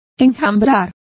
Complete with pronunciation of the translation of swarming.